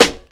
Shady_Snare_3.wav